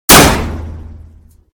/ gamedata / sounds / material / bullet / collide / metal02gr.ogg 18 KiB (Stored with Git LFS) Raw History